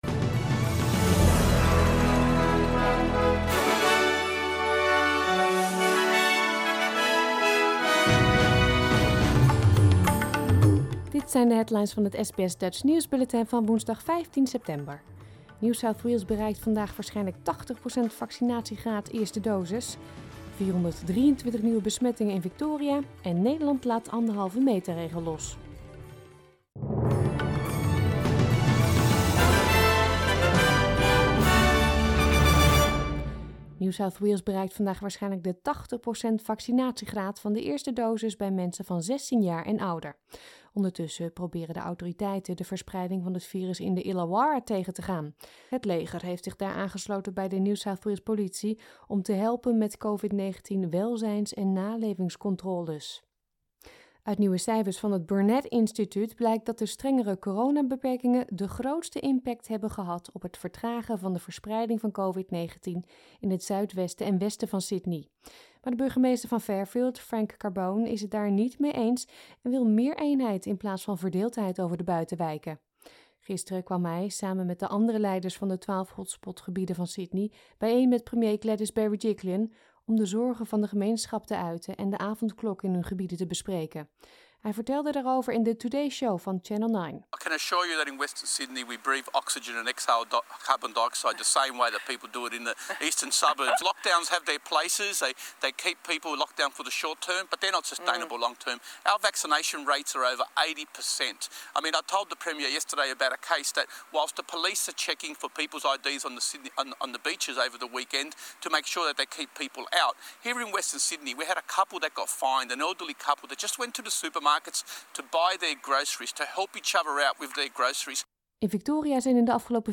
Nederlands/Australisch SBS Dutch nieuwsbulletin van woensdag 15 september 2021